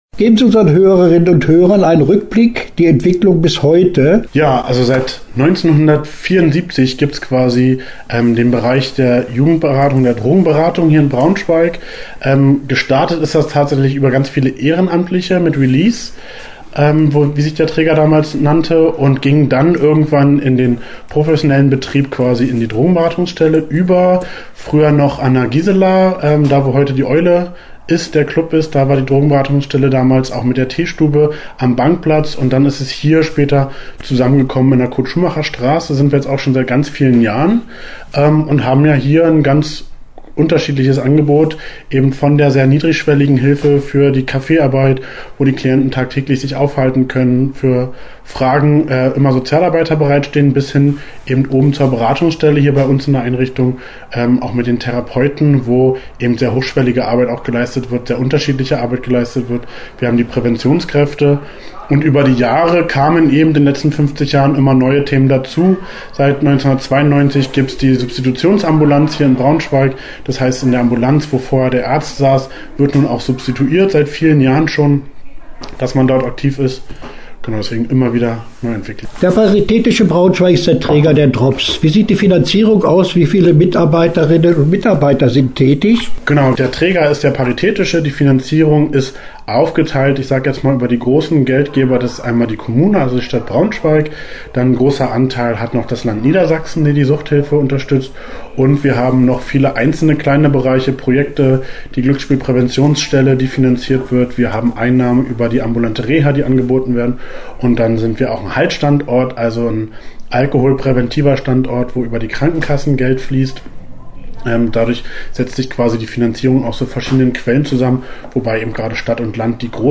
Interview-50-Jahre-Drobs.mp3